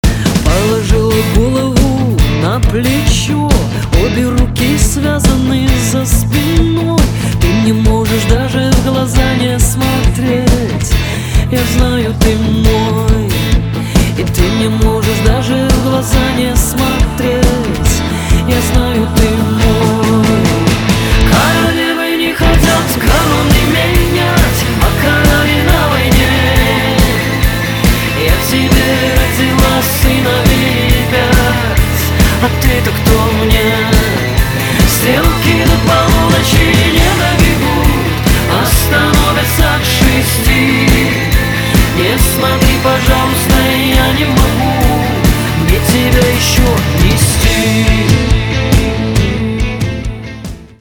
• Качество: 320, Stereo
гитара
женский вокал
русский шансон
поп-рок
эстрадные
барабаны